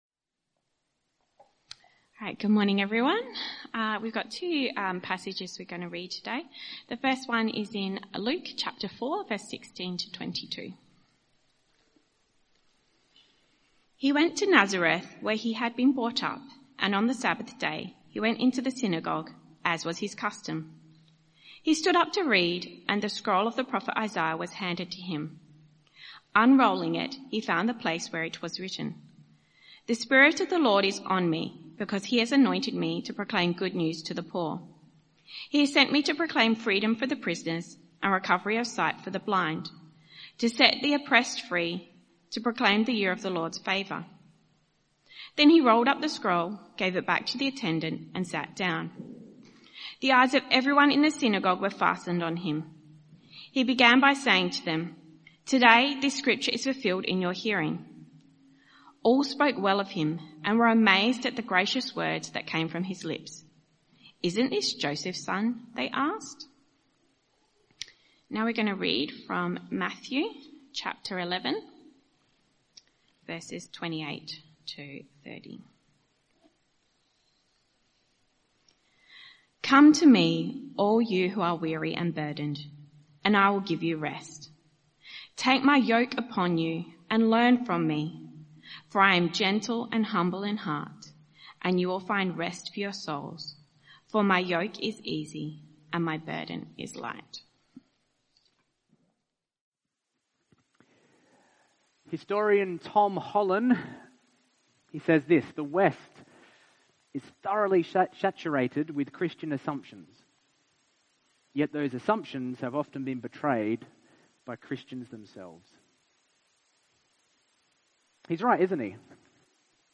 Talk Summary